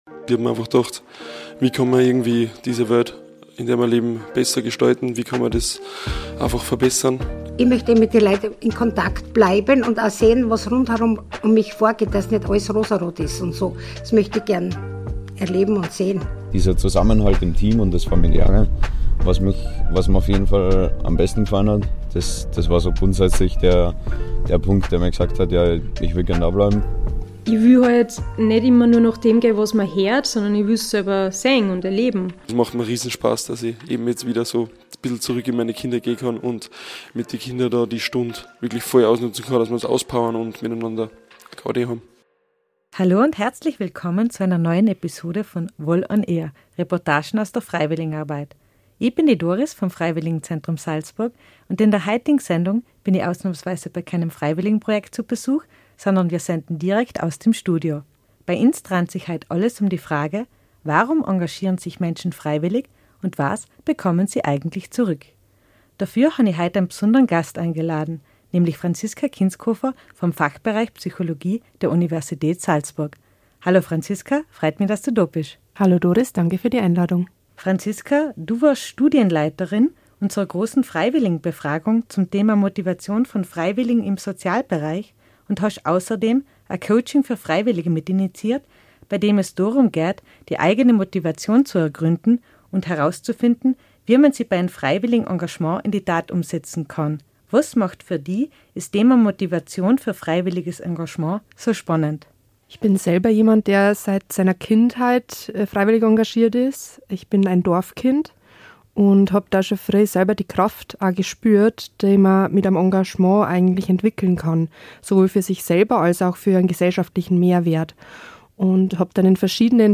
#12 Studio-Gespräch: Warum engagieren sich Freiwillige? ~ VOL ON AIR –- Reportagen aus der Freiwilligenarbeit Podcast